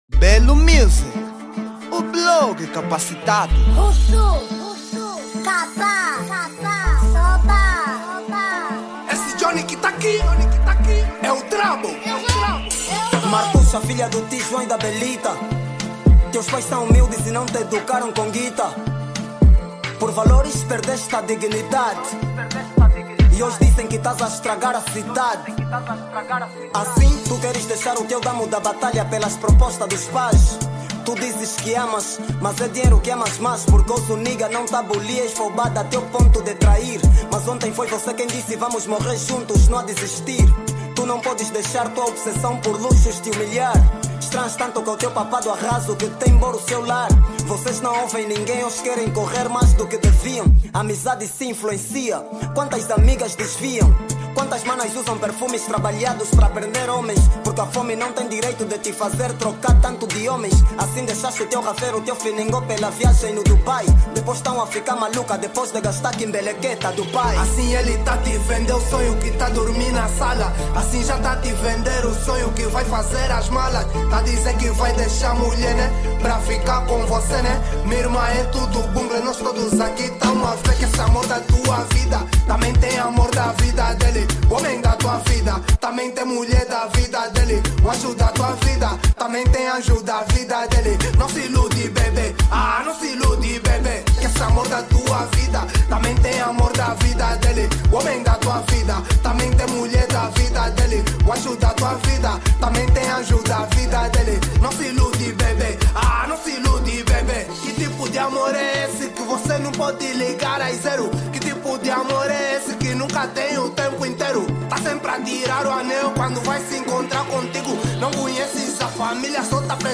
Gênero : Kuduro